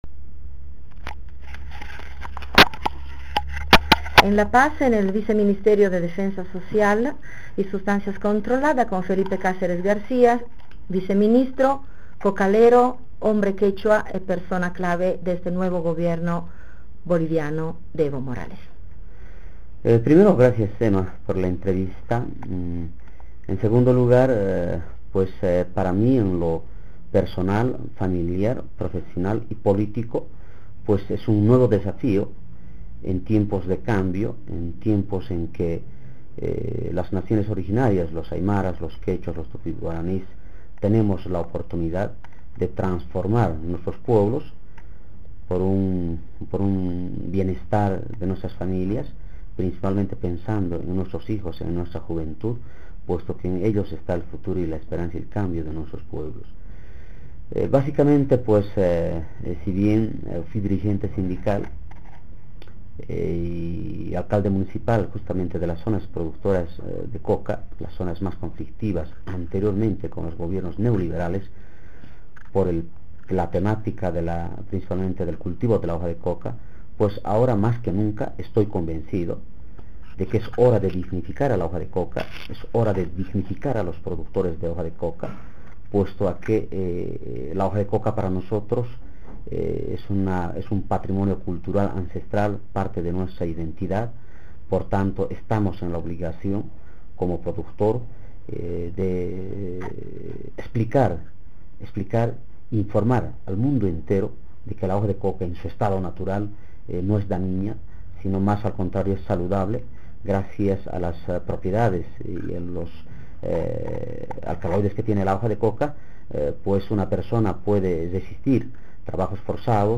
intervista.mp3